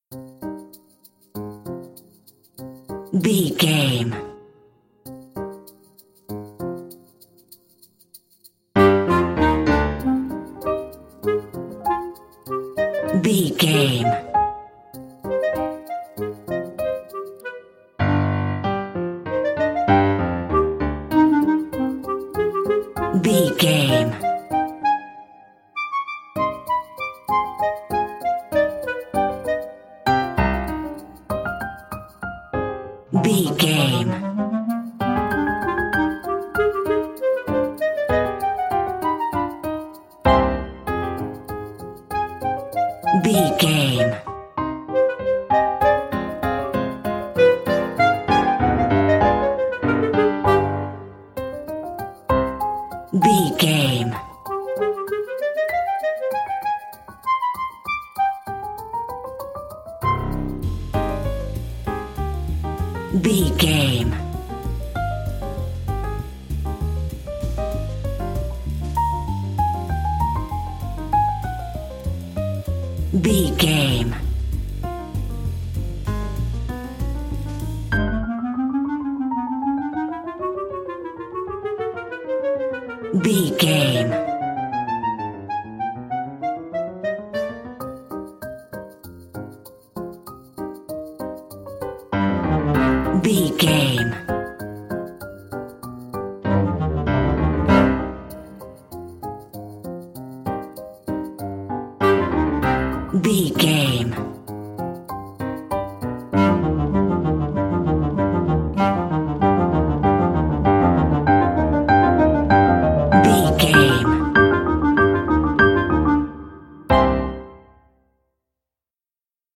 Uplifting
Aeolian/Minor
flute
oboe
strings
circus
goofy
comical
cheerful
Light hearted
quirky